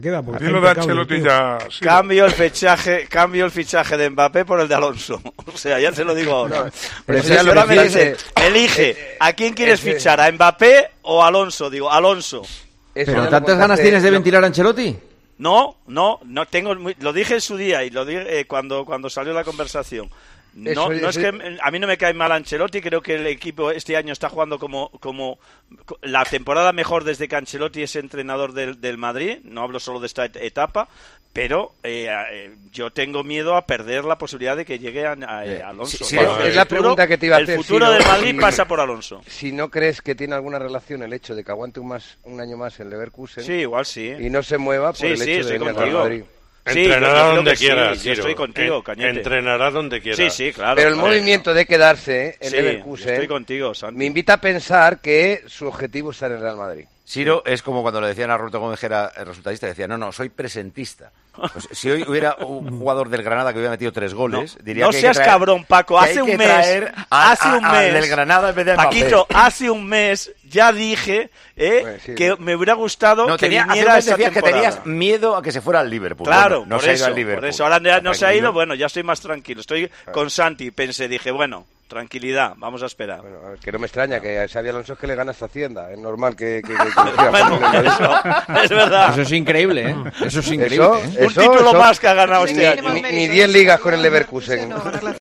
El comentarista del Tertulión de Tiempo de Juego habló del gran trabajo de Xabi Alonso con el Bayer Leverkusen tras ganar la Bundesliga por primera en su historia.